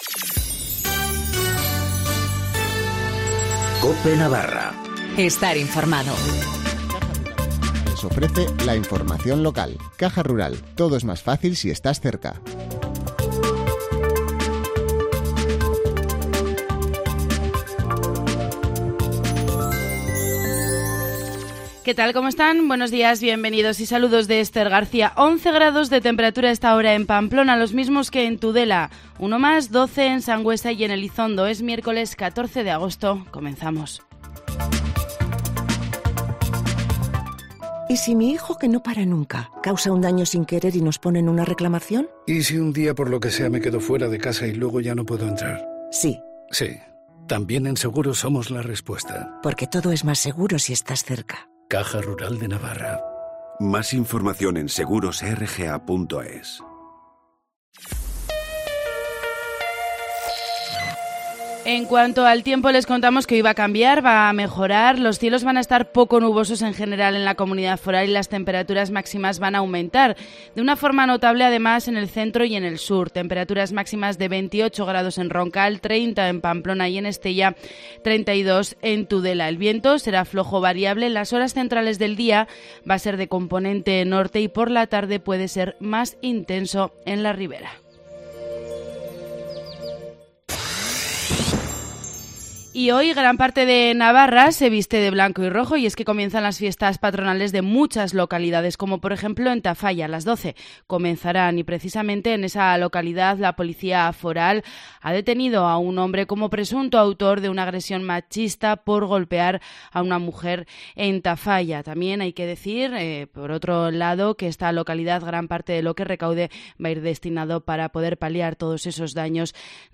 Informativo matinal del 14 de agosto